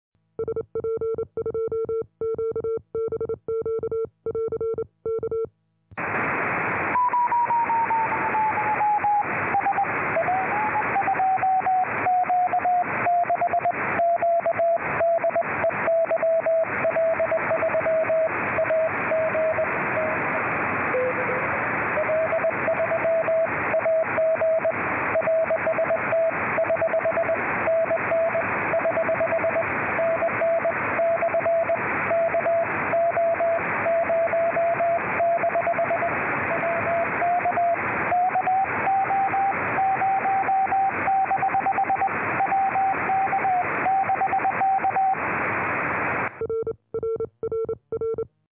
QSO SP-YL w paśmie 3 cm w zawodach SPAC/NAC
Jak zwykle pacowałem z balkonu, mając idealne wyjście w stronę YL przeprowadziłem łączność w super warunkach.